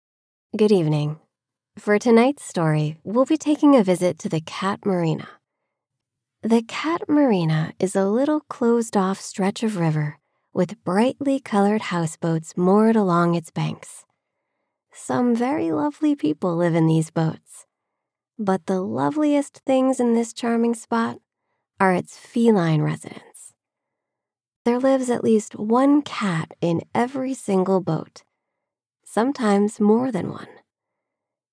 Female
American English (Native)
My voice sits in the millennial / Gen Z range – from early 20s to 40s, with a General American accent.
IVR:Phone System Sample.mp3
Microphone: Sennheiser MKH416